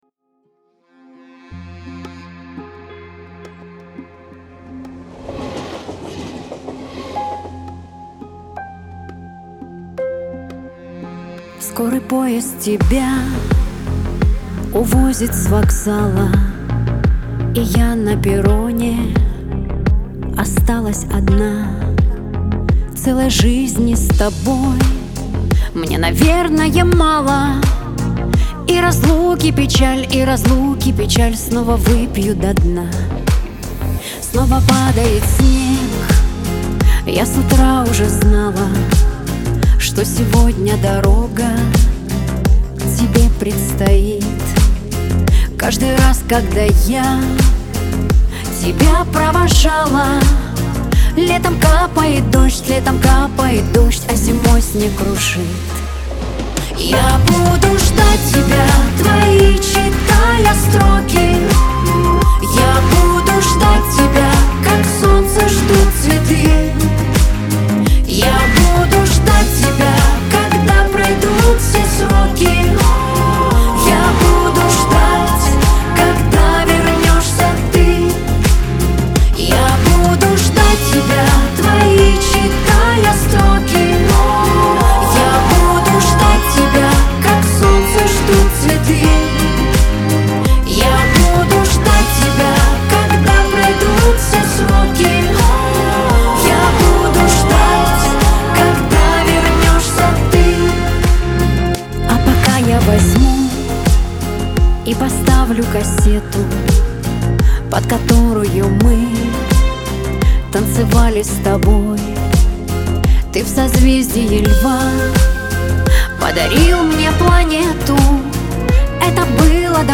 Трек размещён в разделе Русские песни / Шансон / 2022.